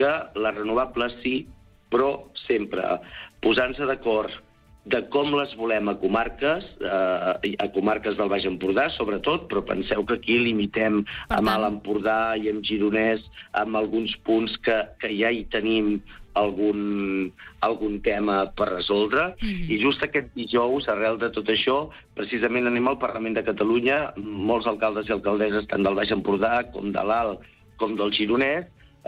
Entrevistes SupermatíSupermatí